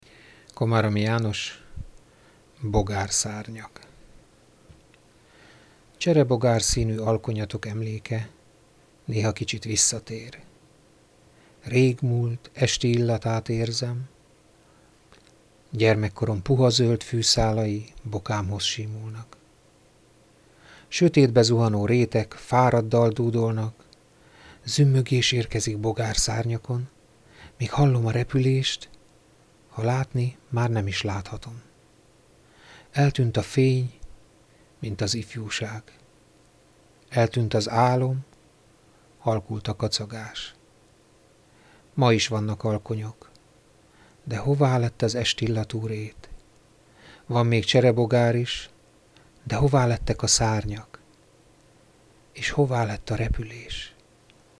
Egyszer azonban gondoltam egyet és elmondtam néhány versemet.